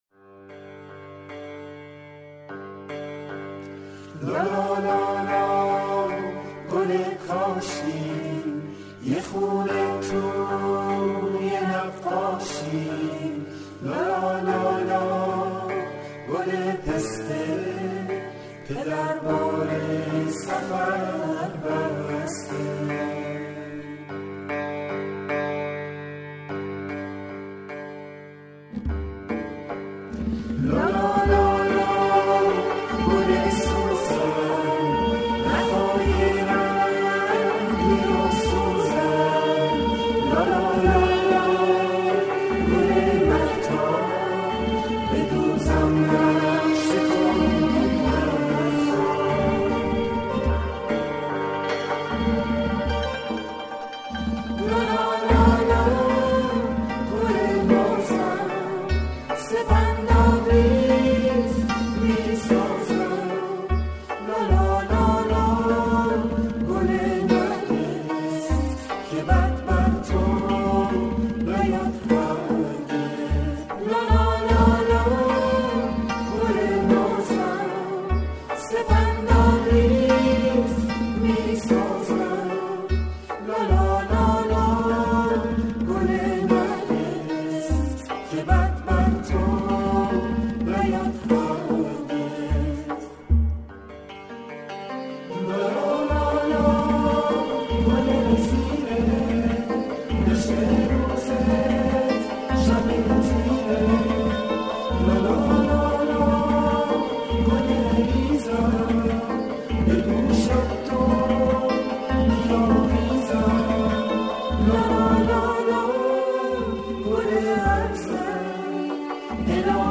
لالایی
آهنگ لالایی